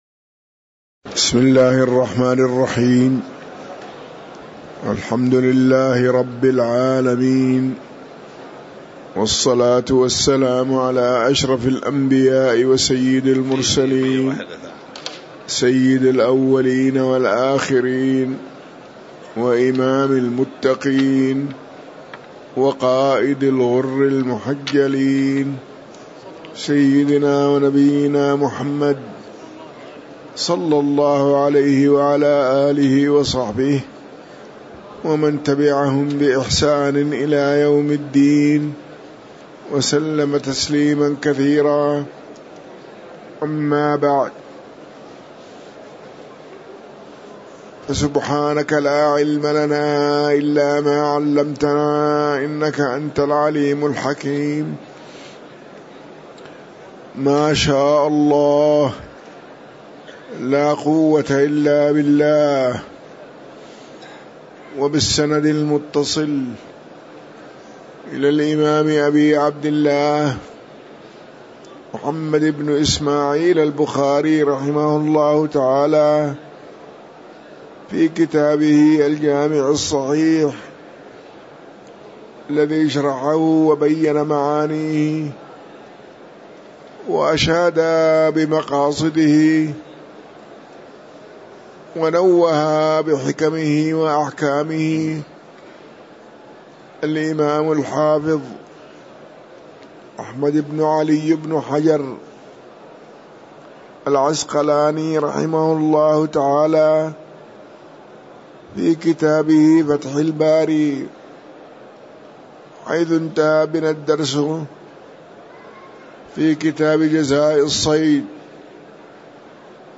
تاريخ النشر ١٣ شوال ١٤٤٤ هـ المكان: المسجد النبوي الشيخ